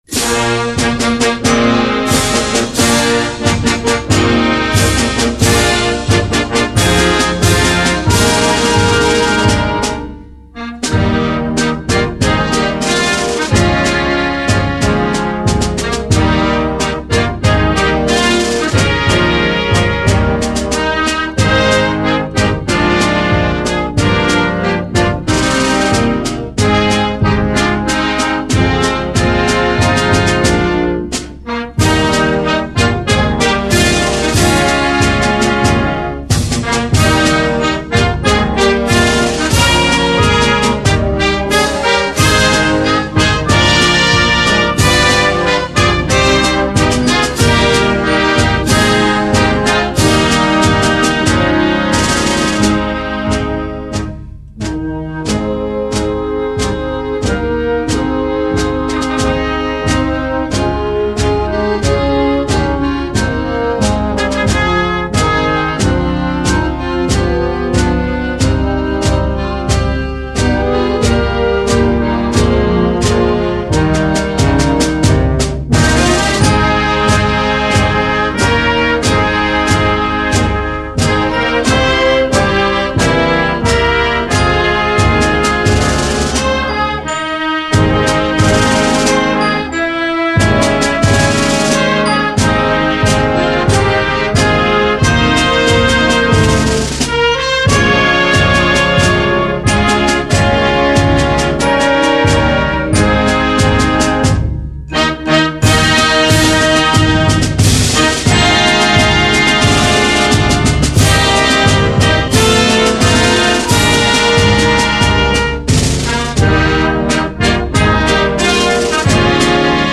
23 x 30,5 cm Besetzung: Blasorchester PDF